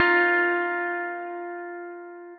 Campfire Guitar.wav